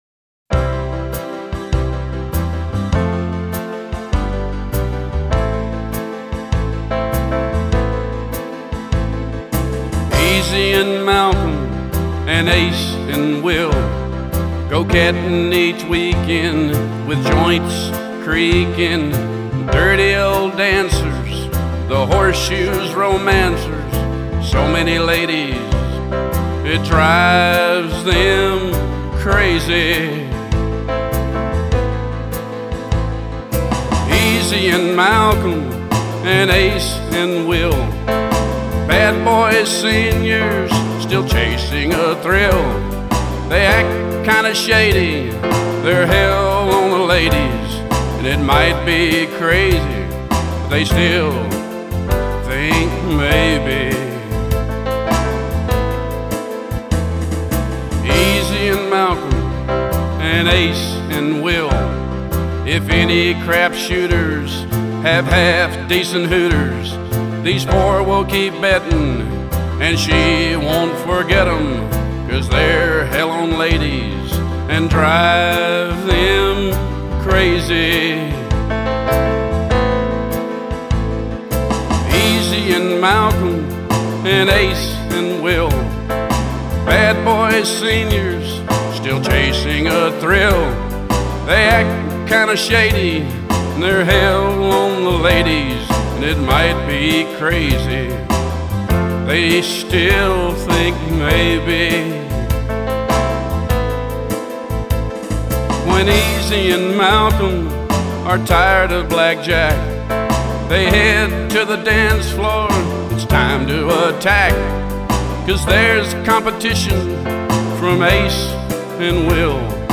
In the song, I wrote something about trains so he could blow his train whistle.
but here is a humorous ditty about